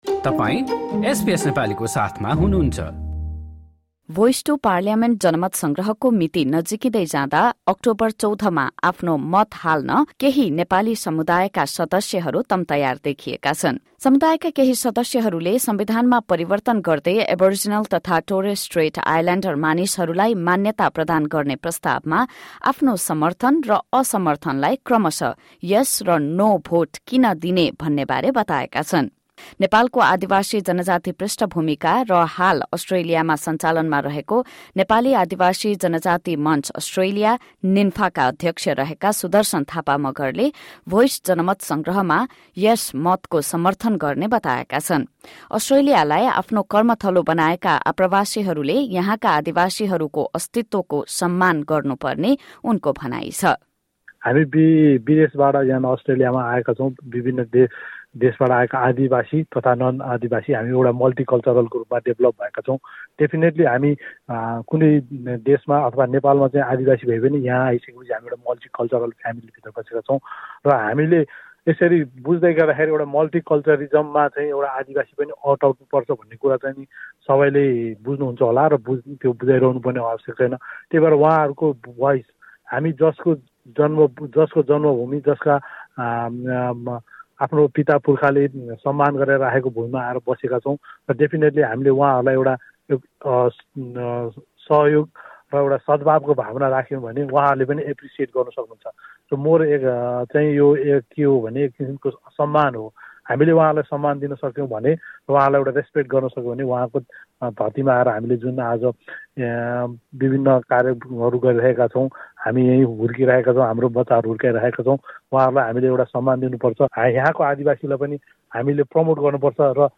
र 'भोइस टु पार्लियामेन्ट' जनमत सङ्ग्रहमा नेपाली समुदायका केही सदस्यले खुलेरै 'येस' र 'नो' को पक्षमा आफ्ना धारणा राखेका छन्। यसबारे एक रिपोर्ट।